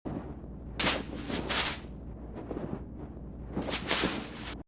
SFX